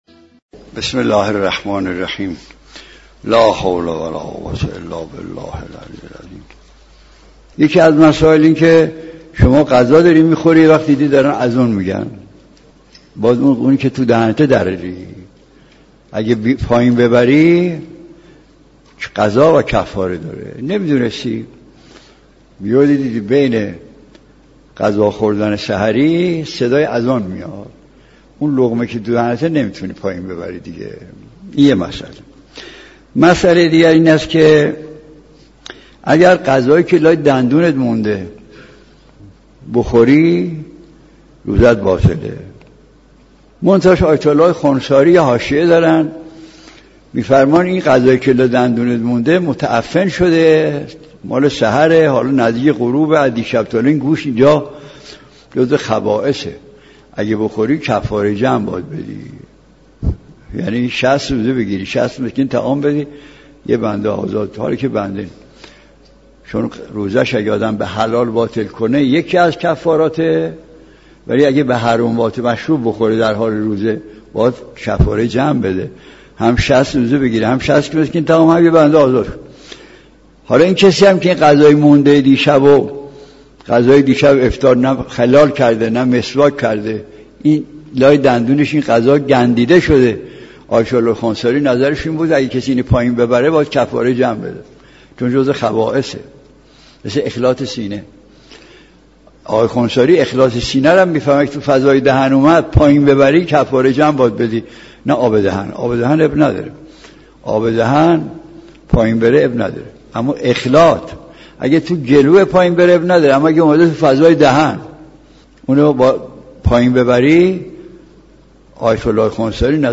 سخنرانی آیت‌الله مجتهدی تهرانی را در ادامه می‌شنوید.